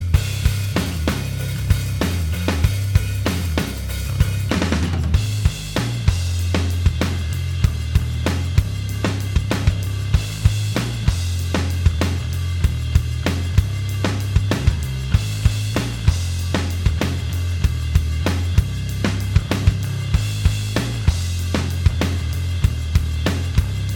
Minus Main Guitars Rock 3:35 Buy £1.50